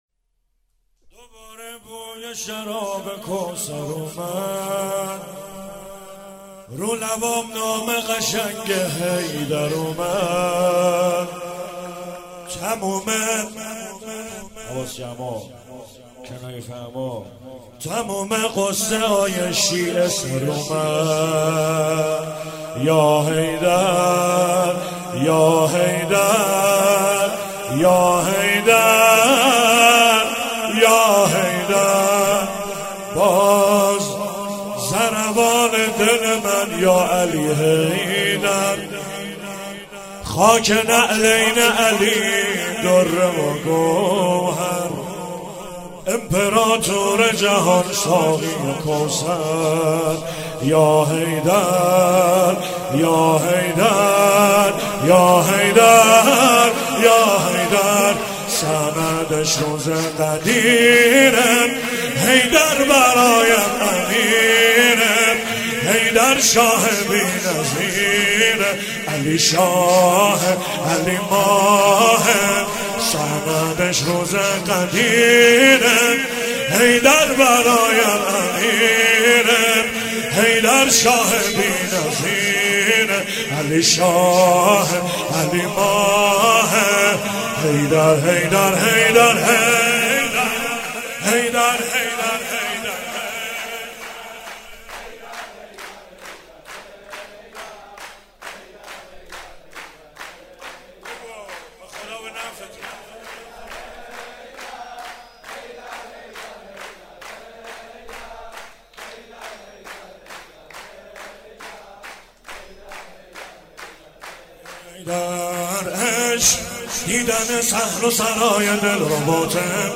ولادت رسول الله و امام صادق (صلی الله علیهما وآلهما)
شور